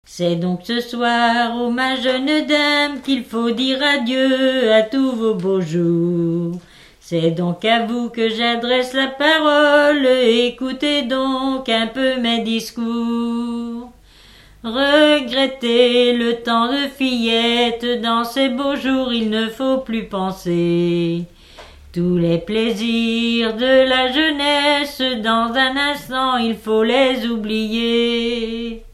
Talmont-Saint-Hilaire
circonstance : fiançaille, noce
Genre strophique
Pièce musicale inédite